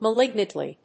アクセント・音節ma・líg・nant・ly